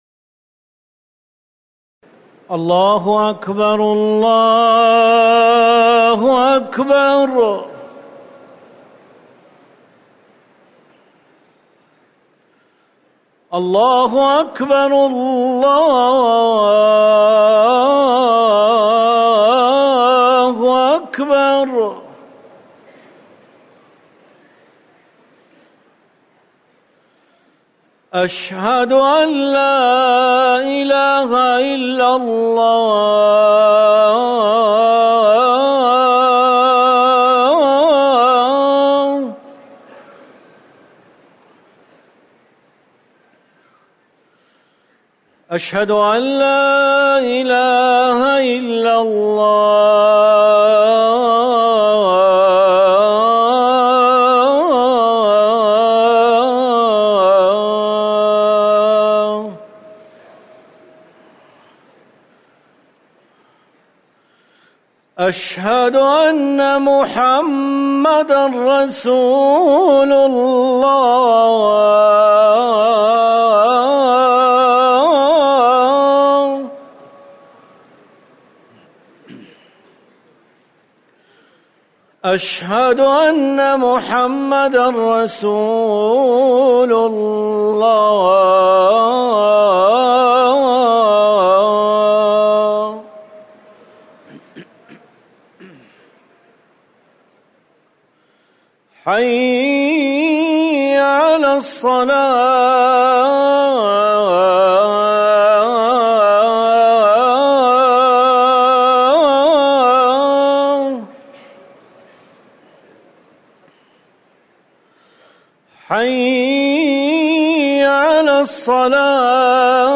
أذان العصر
تاريخ النشر ٤ محرم ١٤٤١ هـ المكان: المسجد النبوي الشيخ